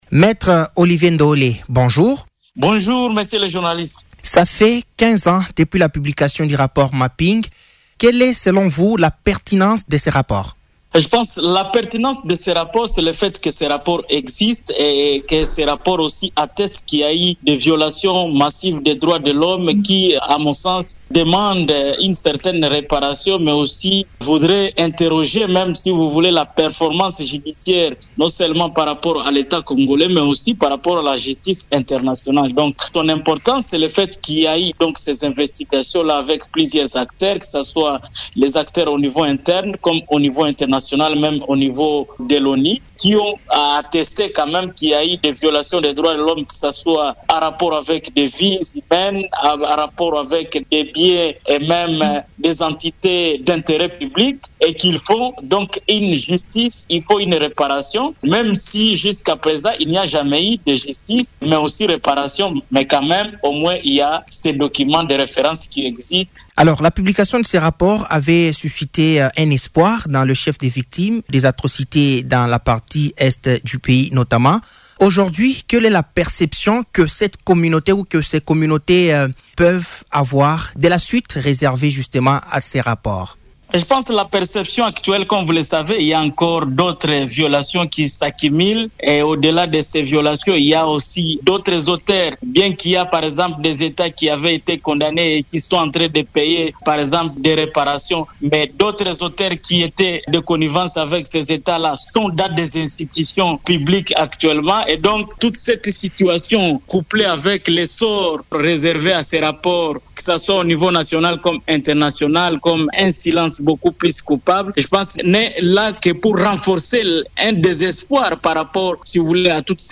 Cet activiste de la société civile condamne le fait que certains Congolais recourent aux armes pour accéder aux postes de responsabilité en RDC, ce qui bloque le processus de lutte contre l’impunité.